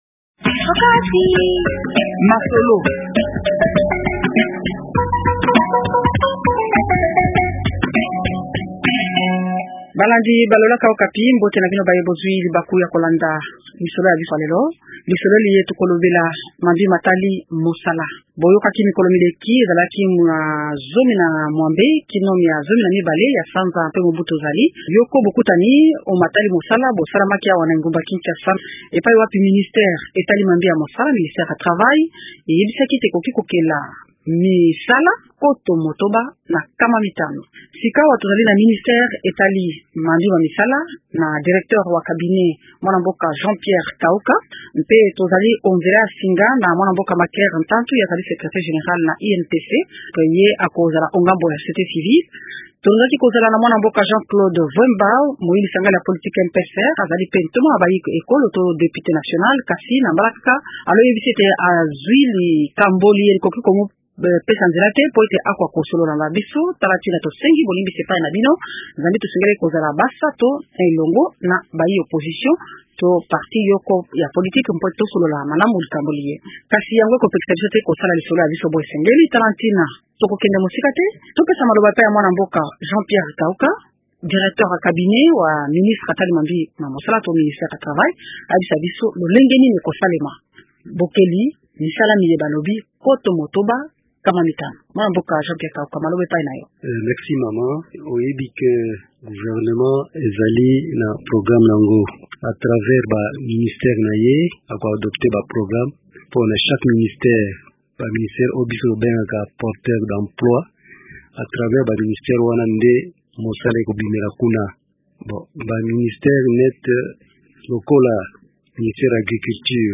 Bamonisi ba opposition, majorité au pouvoir bayi société civile banso bazali kopesa bakanisi na bango na ntina ya mama na likambo liye. rnInvités :